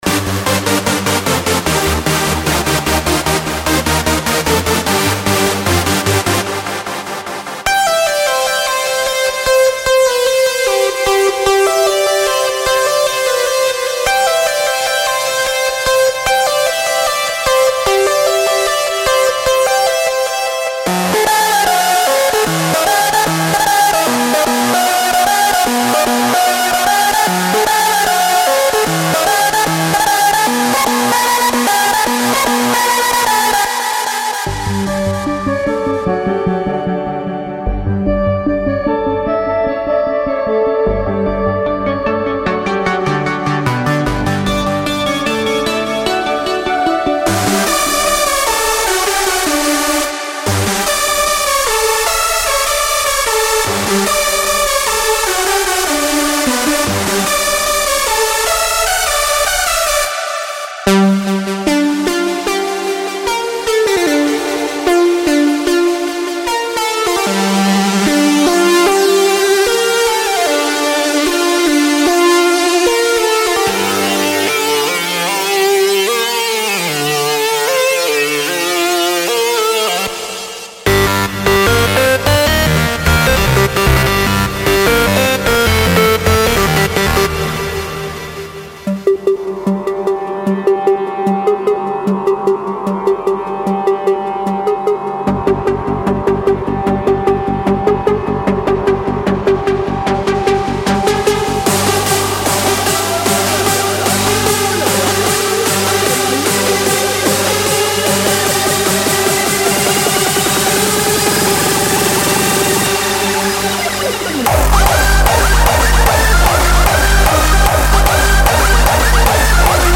这个令人难以置信的包中的所有声音都是从头开始制作的，并具有95种预设，包括尖锐的声音，制作大型即兴即兴的主音，当然还有打击垫，低音和FX元素，因此您可以在专业的Hardstyle音轨上完全构建使用这一套声音。
•6个低音鼓